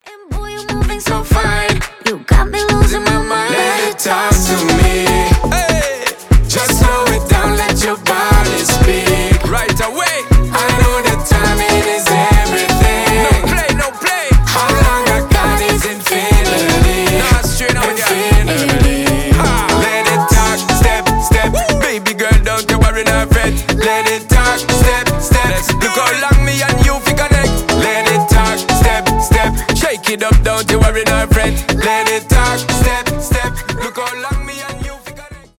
dancehall
танцевальные , заводные